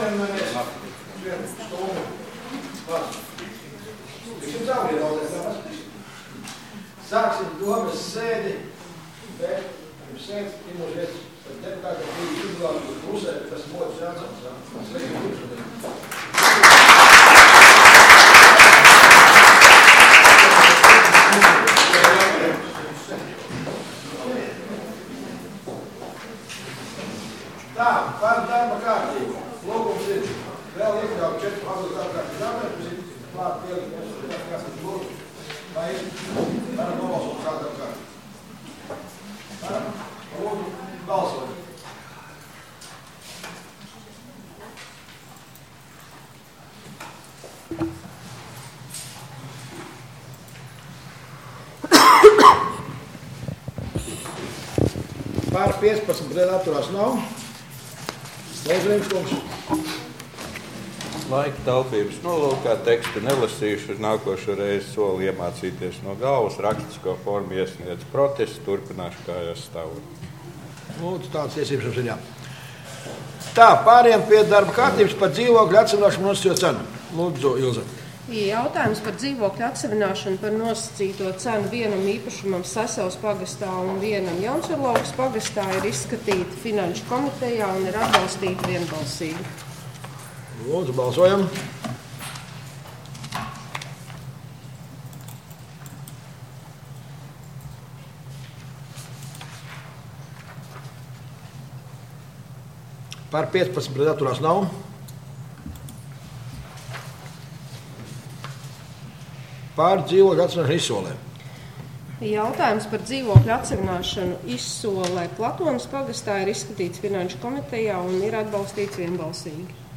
Domes sēde Nr. 3